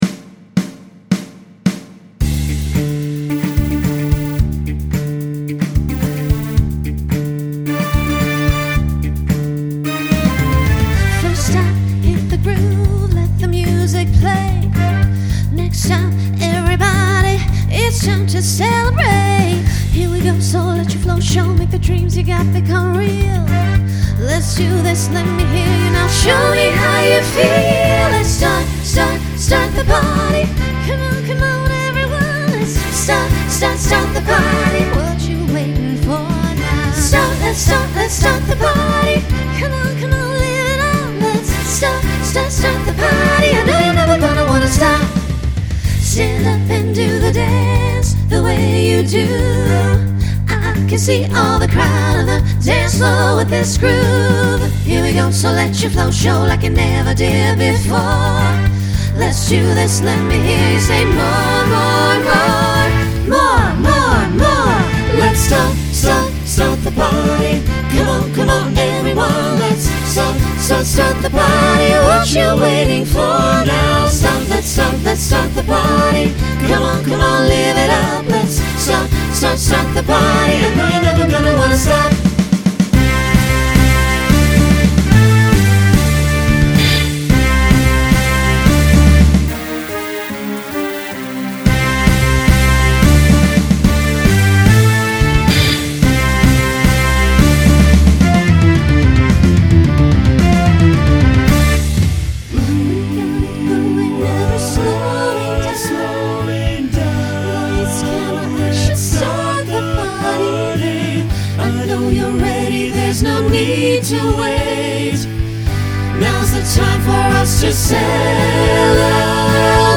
Starts with a treble trio to facilitate a costume change.
Broadway/Film
Transition Voicing Mixed